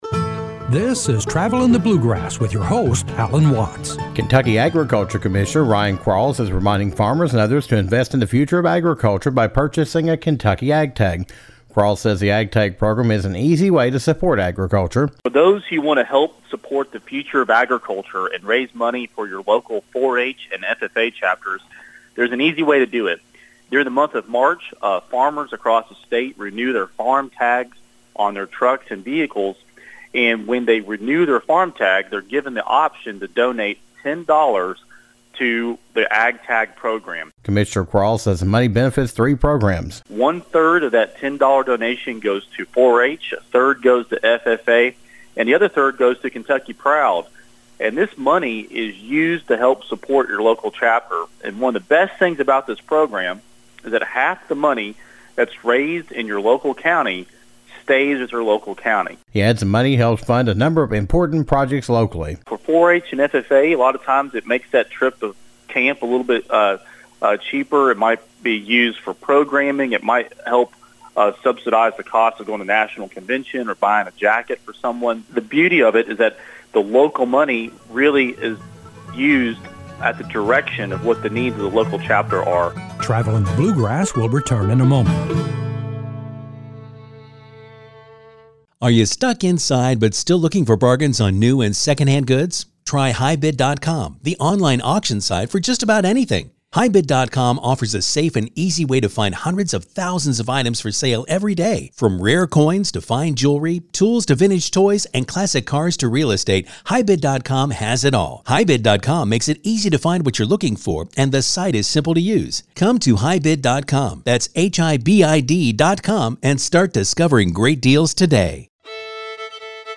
Kentucky Agriculture Commissioner Ryan Quarles discusses the Kentucky Ag Tag Program.  He talks about how people can become involved, how the money is used by 4-H, FFA, and Kentucky, the amount of money the program raises, and other details.